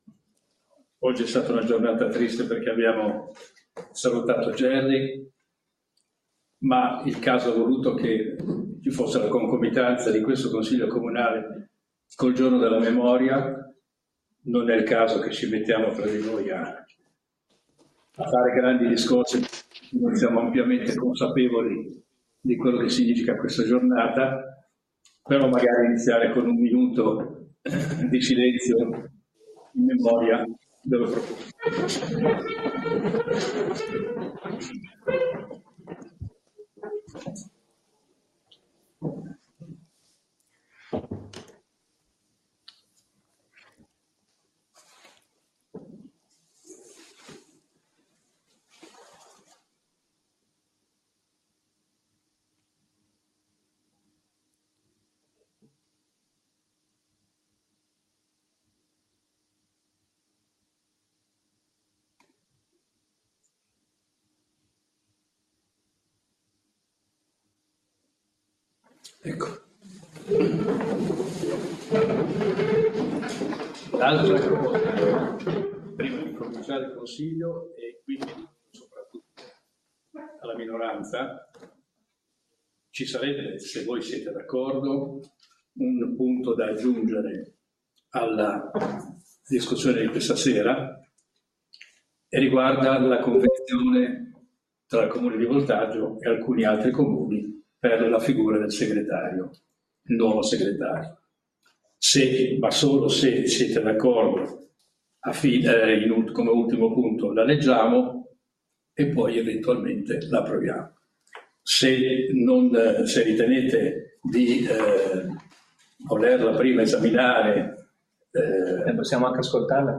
Seduta del Consiglio Comunale del 27/1/2025